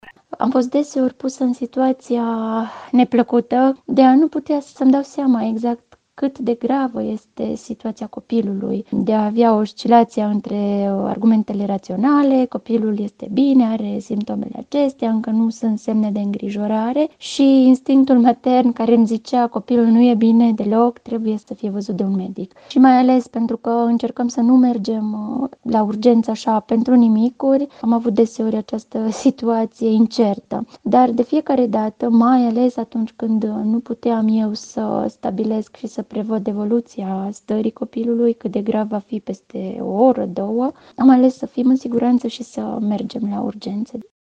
insert-mamica.mp3